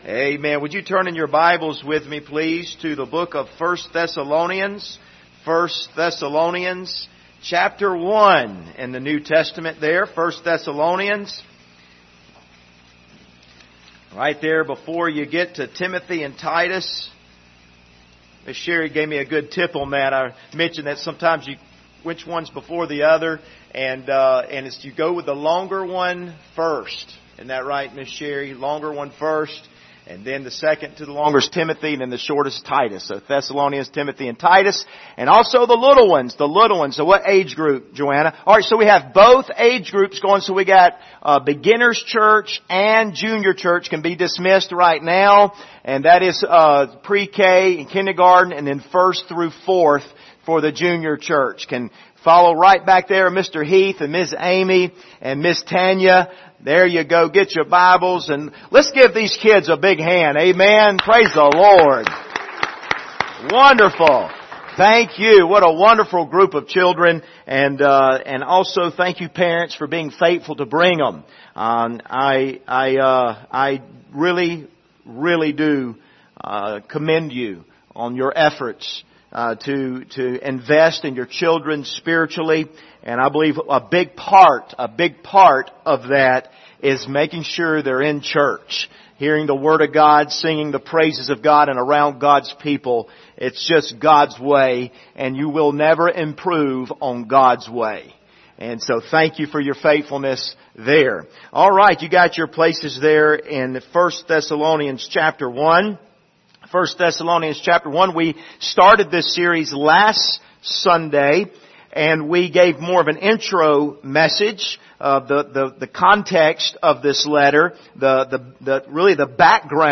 Passage: 1 Thessalonians 1:1-2 Service Type: Sunday Morning